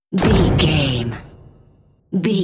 Dramatic hit slam door rvrb
Sound Effects
heavy
intense
dark
aggressive